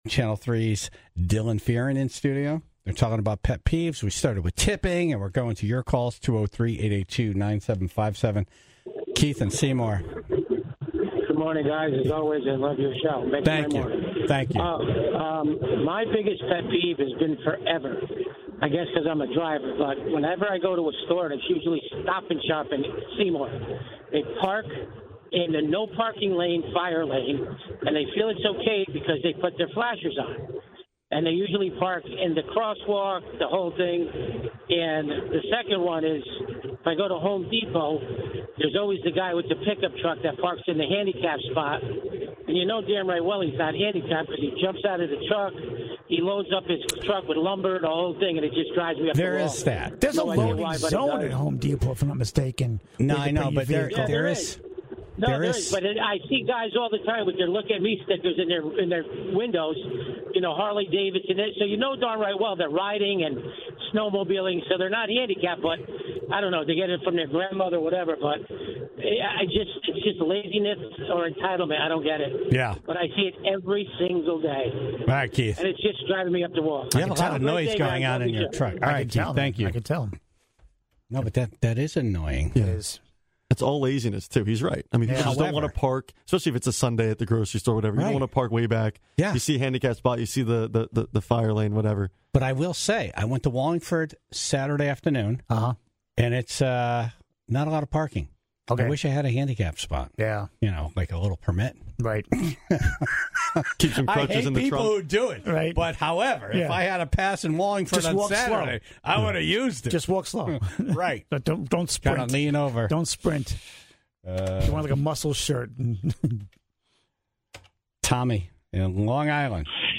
in-studio to discuss their pet peeves, and took calls from the Tribe to add to the list. Many everyday frustrations were vented!